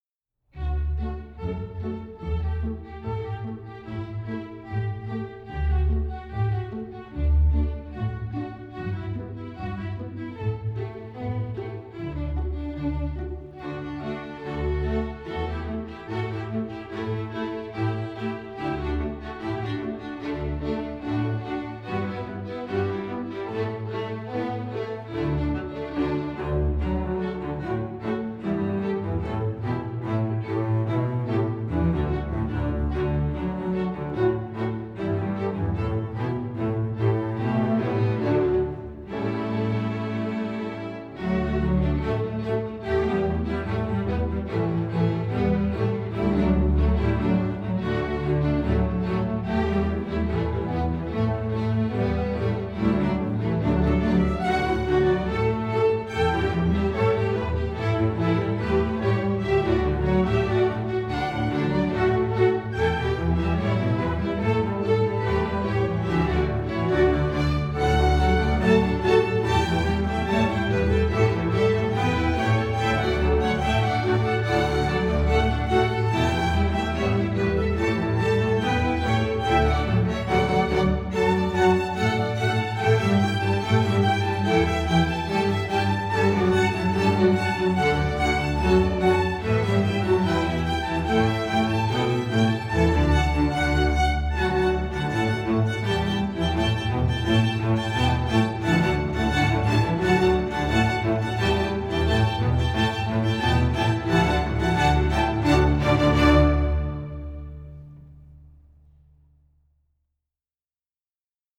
Composer: Slovakian Folk Song
Voicing: String Orchestra